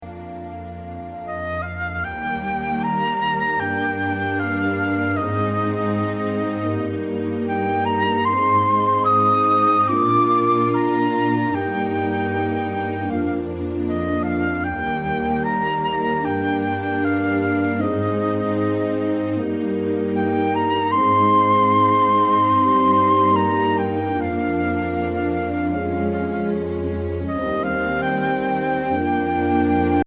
• Play-Alongs für Flöte solo